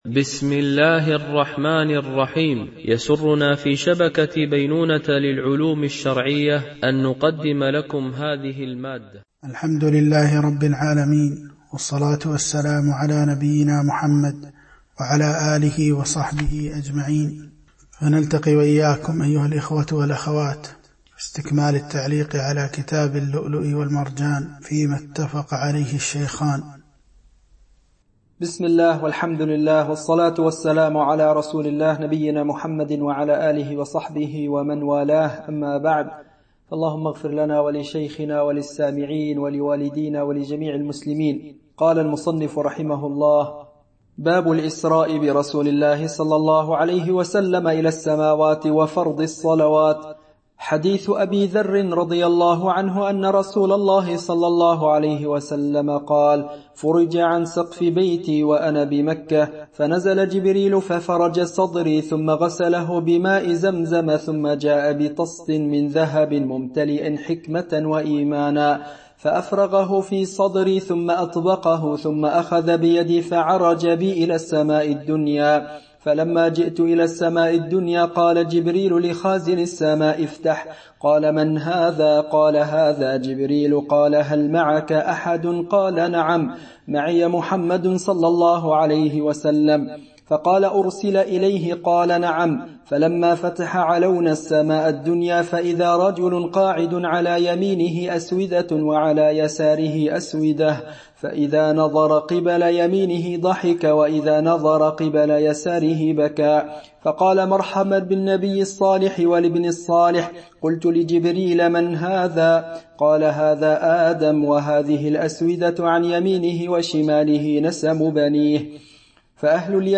الدرس 12